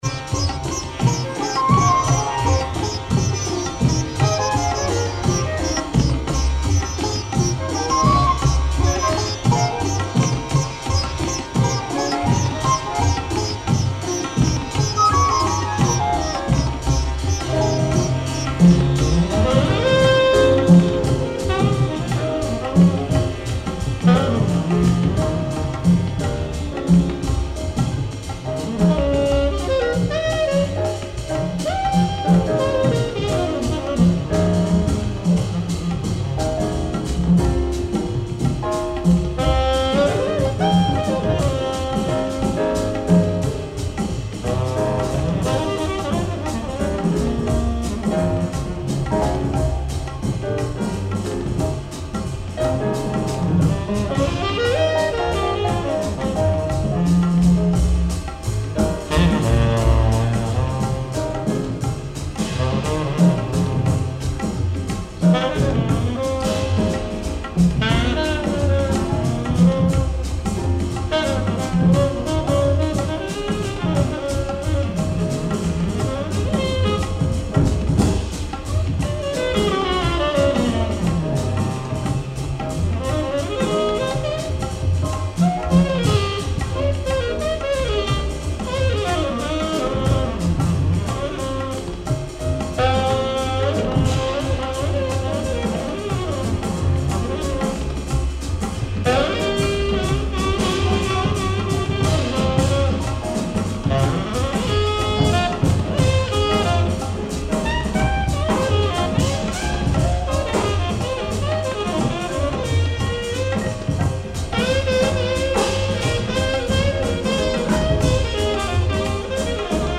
sitar jazz album